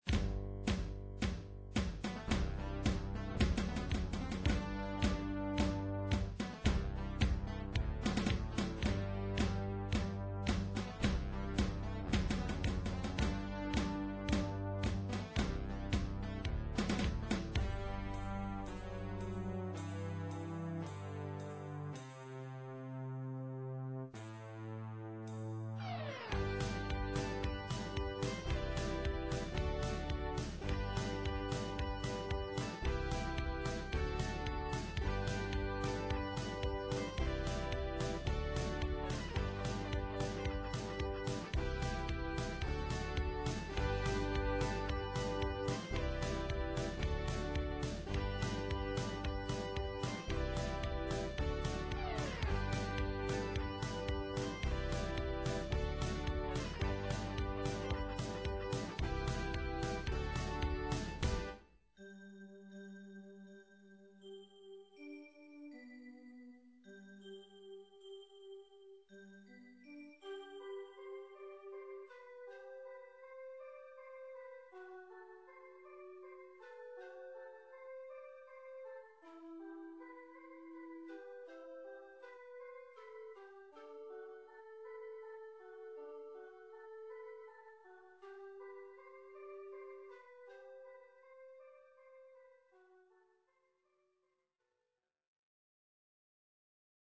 Everything is made in MIDI which gives a thinner sound.
interrupted by the Elfgirl's dance [instr.]